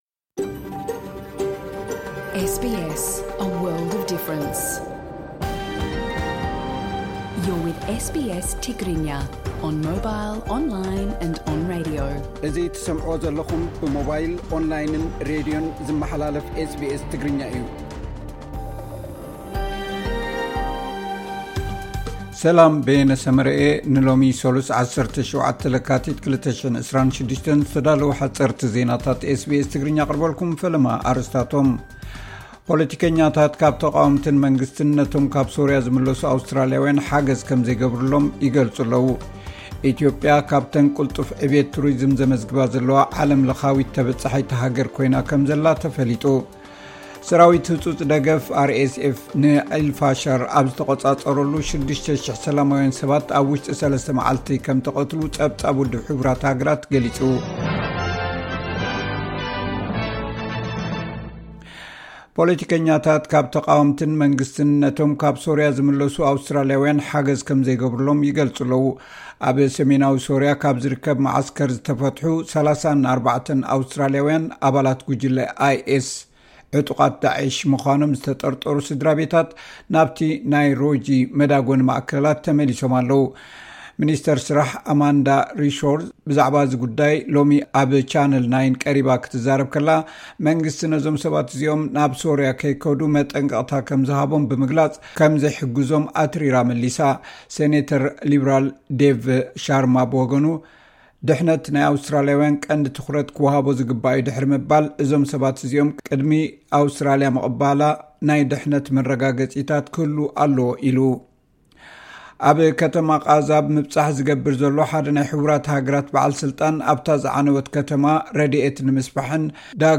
ሓጸርቲ ዜናታት ኤስ ቢ ኤስ ትግርኛ (17 ለካቲት 2026)